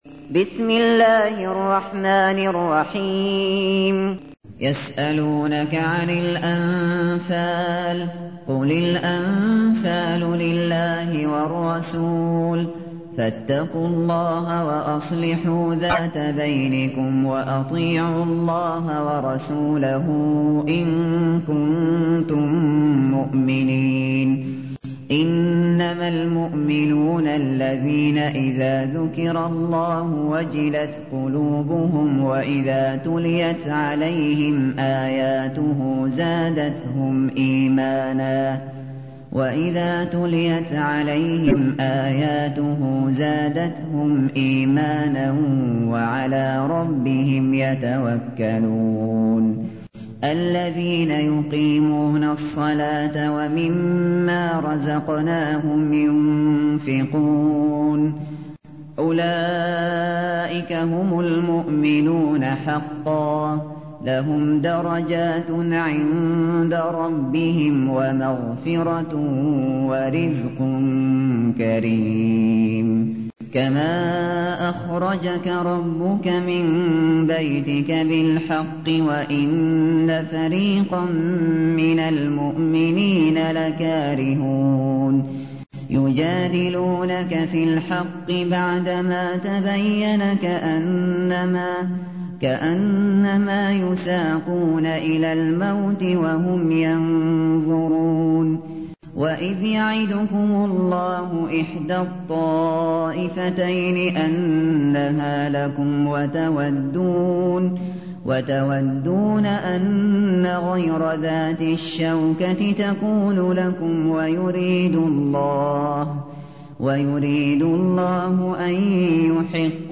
تحميل : 8. سورة الأنفال / القارئ الشاطري / القرآن الكريم / موقع يا حسين